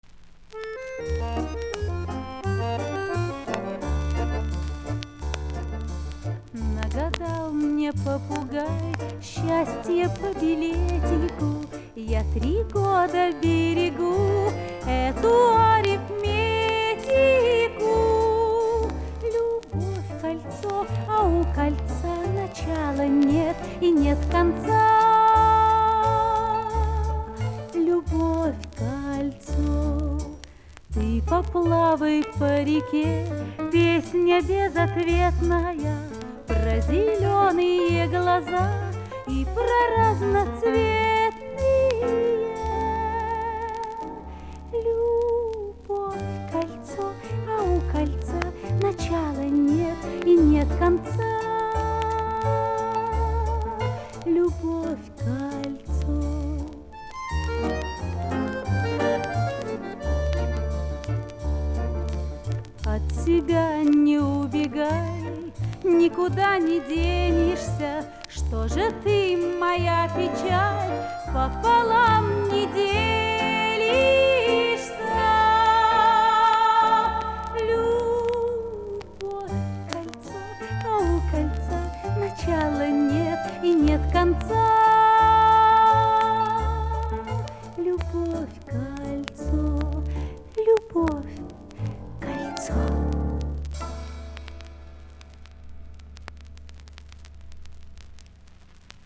Вот с винила 1967 года